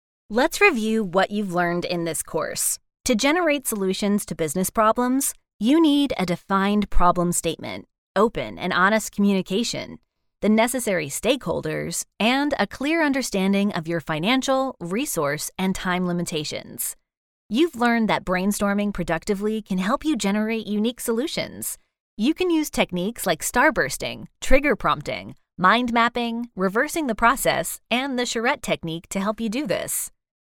Female Voice Over, Dan Wachs Talent Agency.
Bright, young, edgy, real person.
eLearning